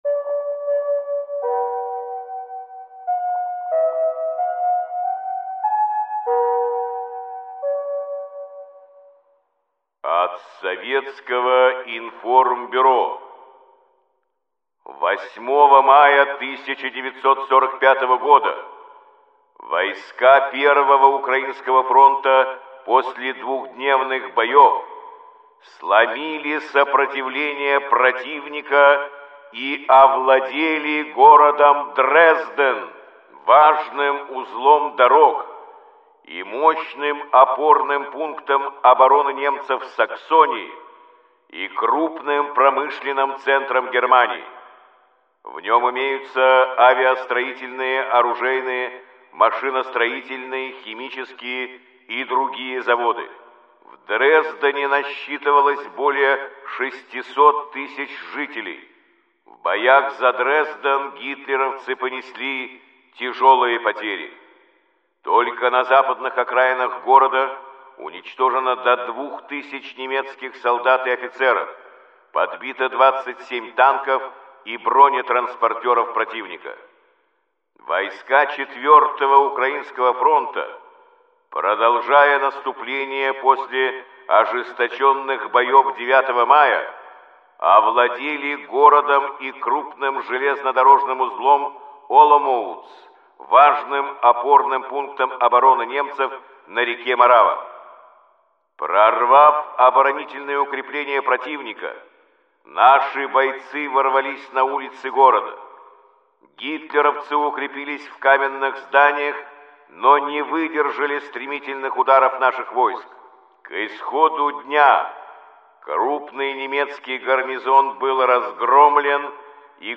Каждый день – это хронология самых важных событий и подвигов героев Великой Отечественной войны, рассказанная в сообщениях СОВИНФОРМБЮРО голосом Юрия Борисовича Левитана.